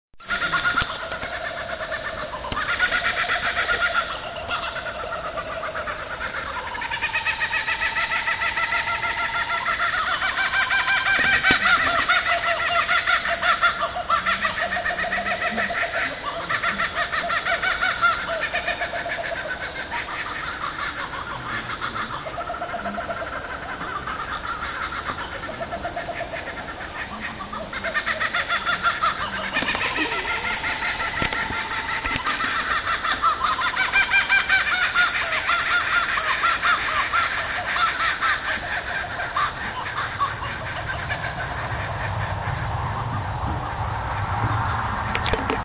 Here is a fabulous audio file of the Laughing Kookaburra:
Truthfully, sometimes we feel like we are in a Planet Of The Apes movie.
laughingkookaburra.mp3